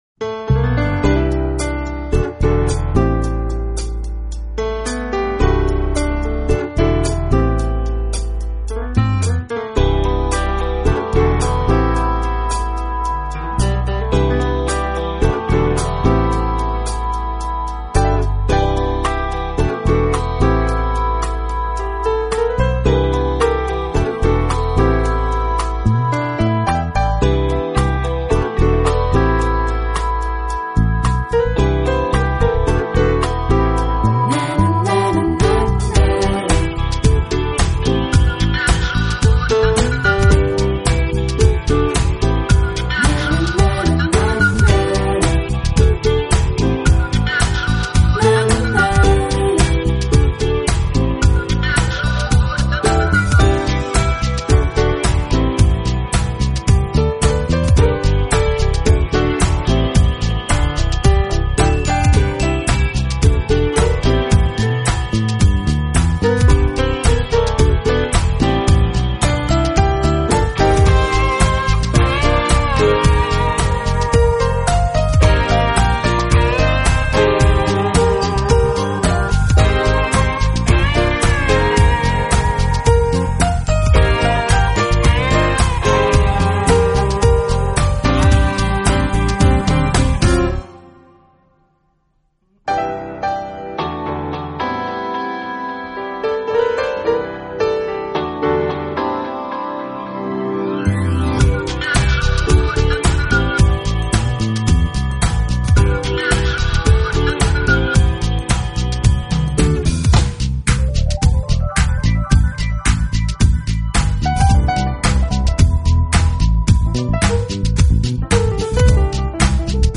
音乐类型: Smooth Jazz / Piano Jazz